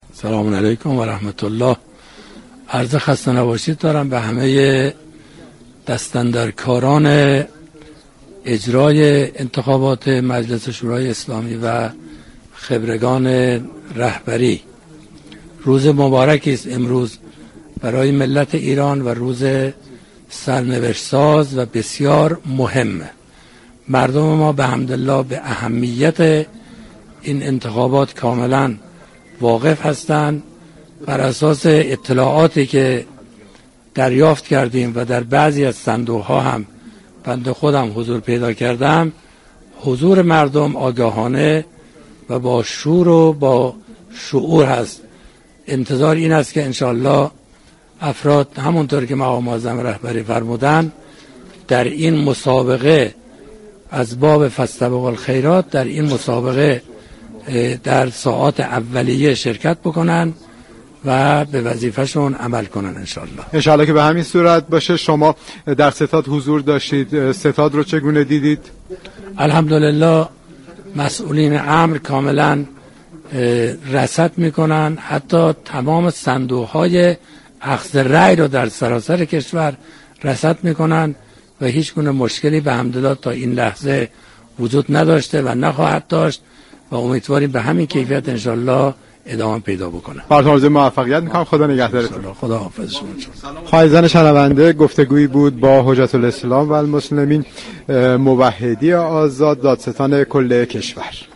مستقر در ستاد انتخابات كشور با حجت‌الاسلام والمسلمین محمد موحدی، دادستان كل كشور گفت و گو كرد.